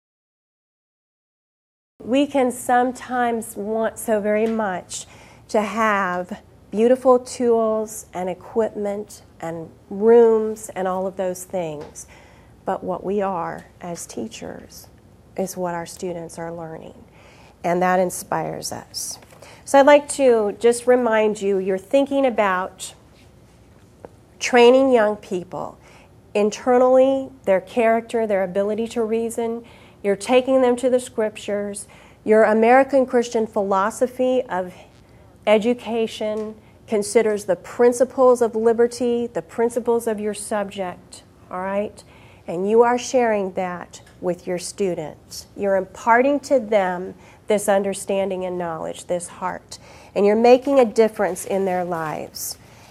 This workshop will highlight Witherspoon's contribution as an American Christian educator and consider his direct influence upon the thinking of the American people.